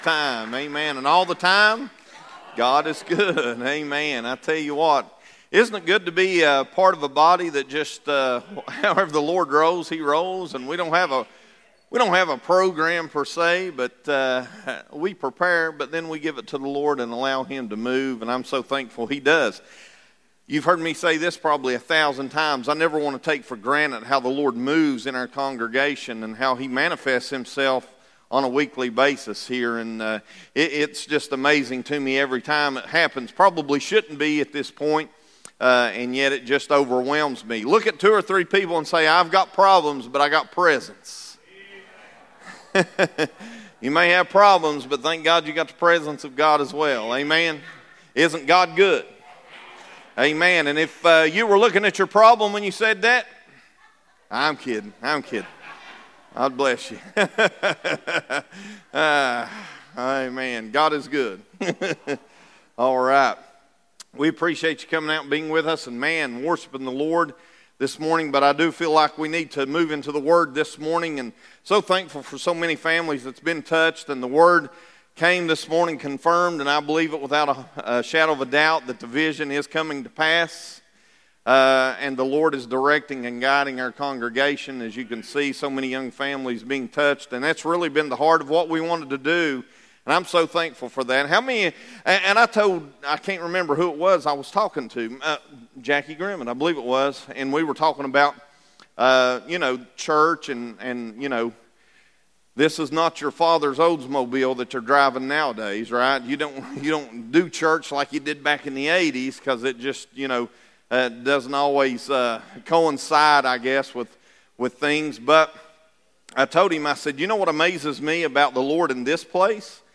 Three Part Sermon Series combating Deception, Distraction, and Division.